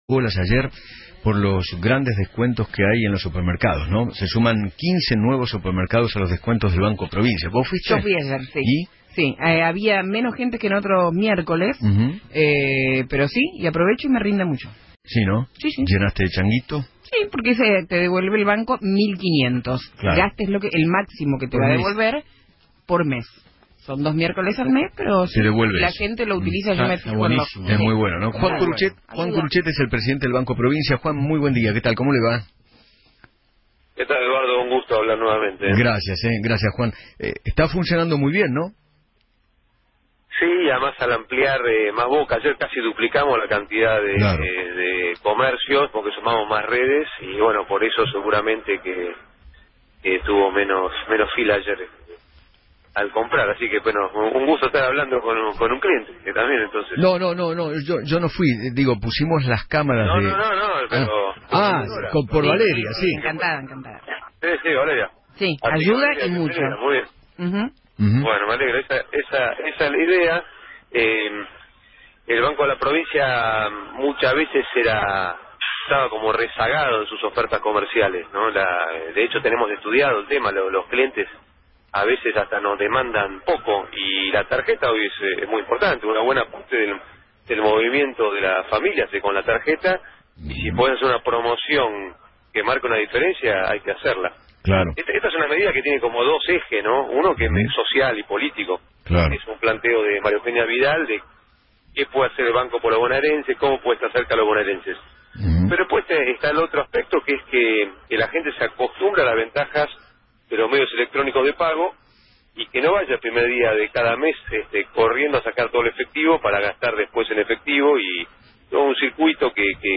Juan Curutchet, Presidente del Banco Provincia: “Casi duplicamos la cantidad de comercios con promoción y por eso hubo menos filas para comprar”.